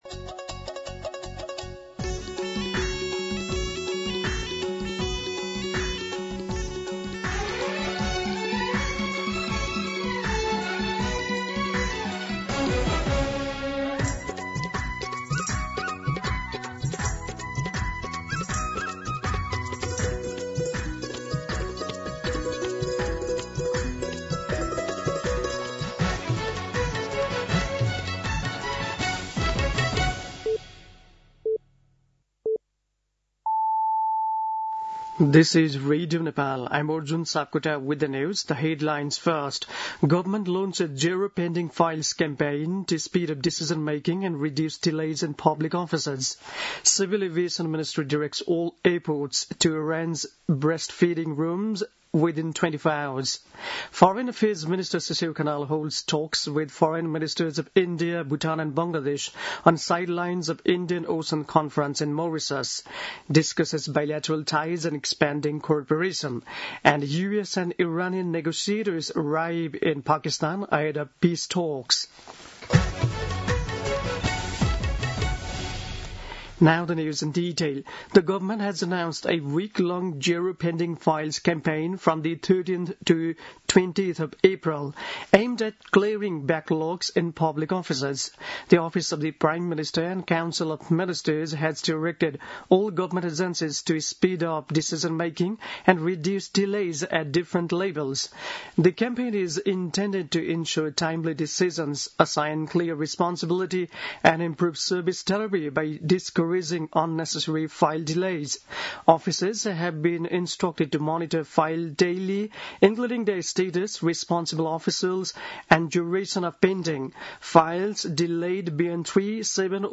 दिउँसो २ बजेको अङ्ग्रेजी समाचार : २८ चैत , २०८२
2pm-English-News-28.mp3